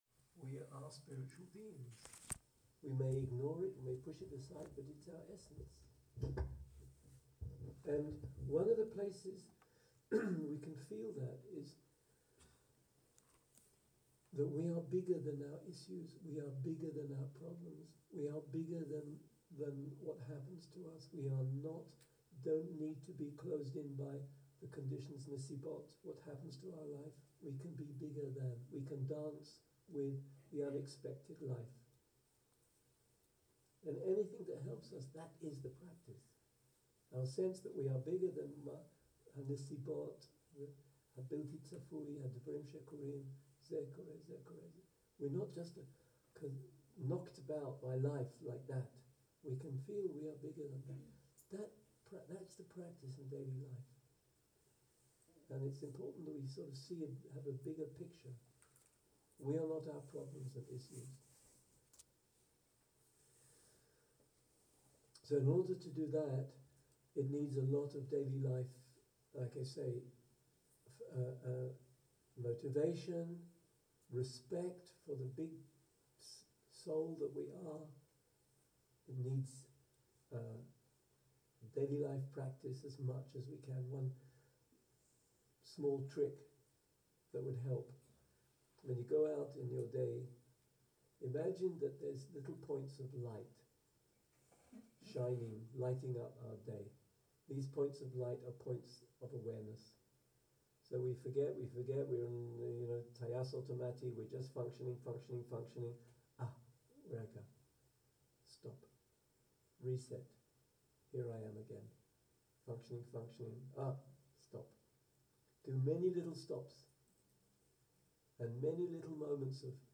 Dharma type: Closing talk שפת ההקלטה